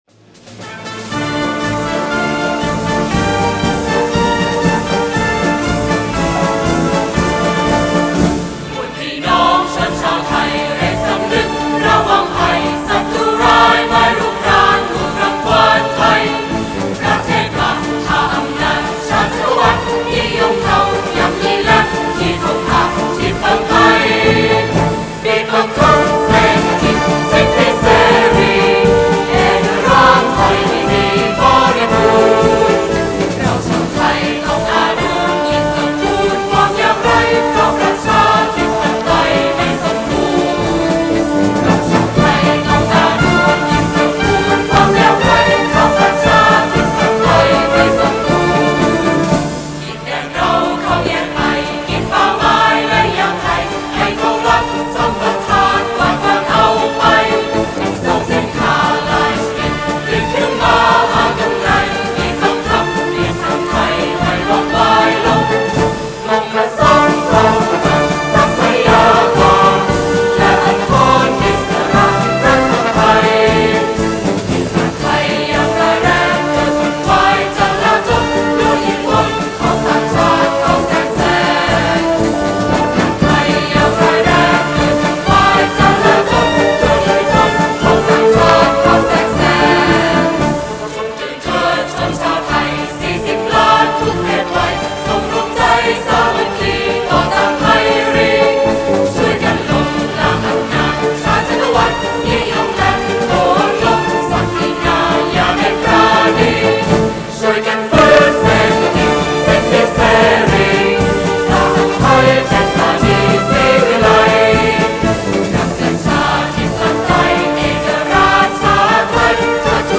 Тайландская революционная песня